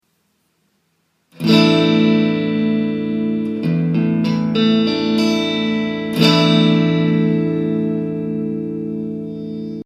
Hörbeispiel zum Asus4 Akkord auf der E-Gitarre:
Asus4 Akkord Hörbeispiel E-Gitarre
asus4.mp3